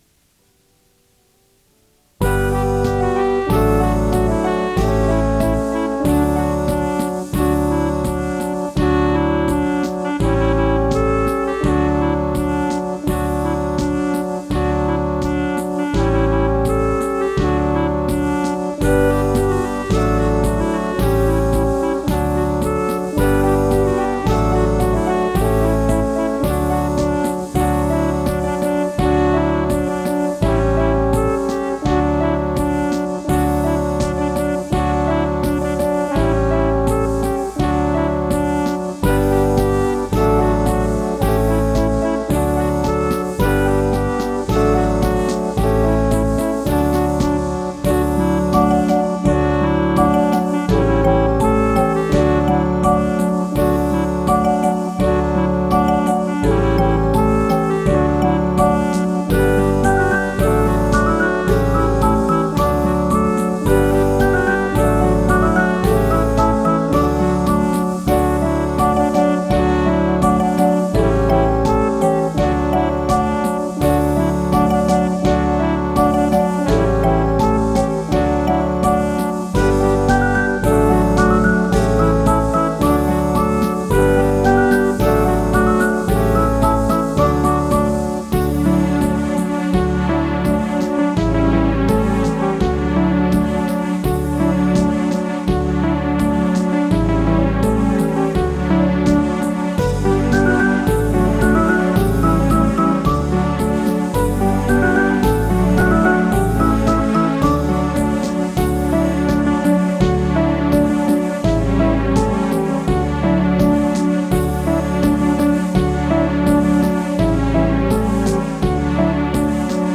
De groene bundel K1, de blauwe bundel K2, de derde bundel K3 heeft geen kleur gekregen maar heeft als titel “Zeg wil je met me dansen” en bevat 22 gezongen kinderdansen.